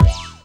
Index of /90_sSampleCDs/Best Service Dance Mega Drums/BD HIP 02 B